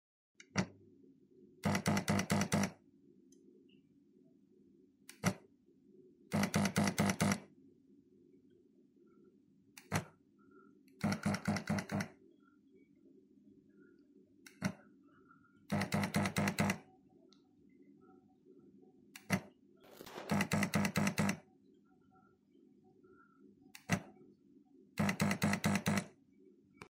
Bruit climatiseur Atantic.mp3
Sur ma climatisation Atantic AFYG-7 KMCCUI elle semble fonctionner normalement, à part que, même arrêtée, un bruit se fait entendre :
Il s'agit d'un bruit répétitif, très régulier, qui fait entendre 5 petit tocs, un peu comme un claquement de relais.
bruit-clim-atantic.mp3